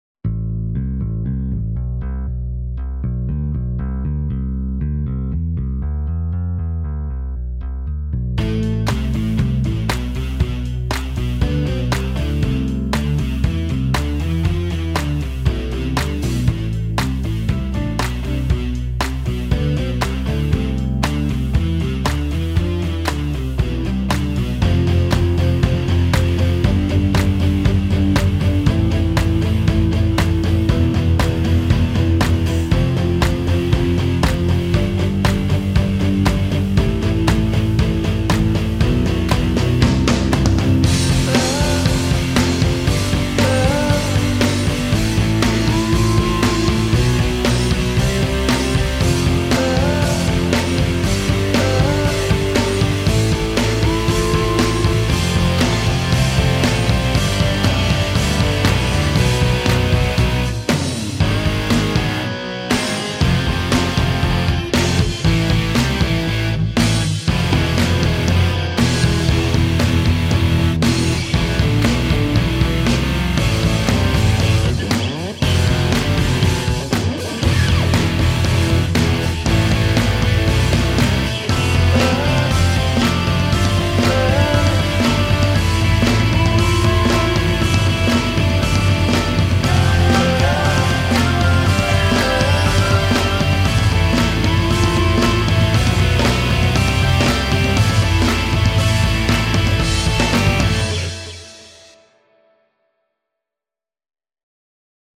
guitare - riffs - rock - pop